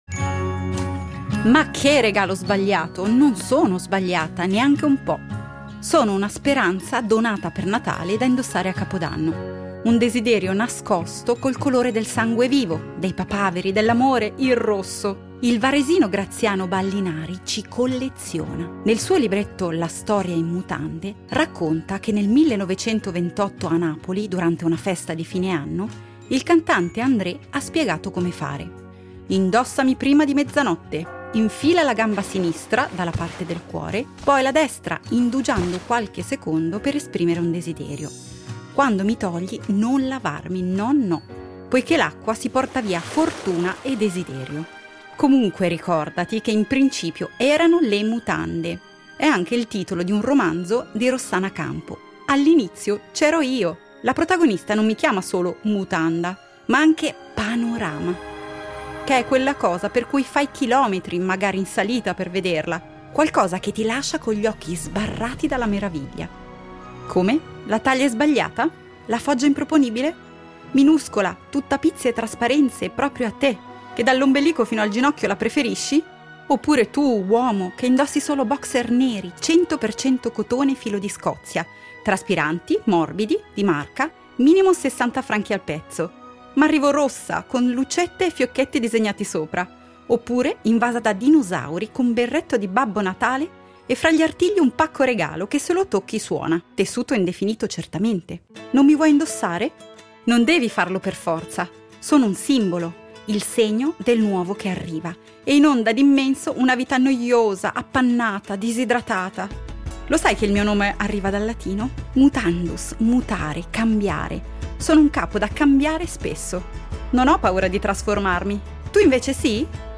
Dieci oggetti, dieci storie narrate in prima persona.